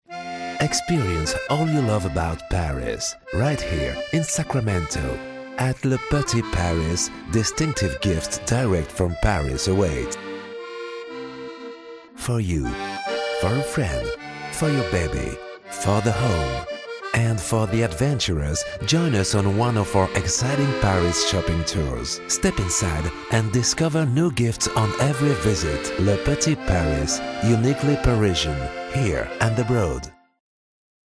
Voix-off pro français grave posée profonde
Sprechprobe: Sonstiges (Muttersprache):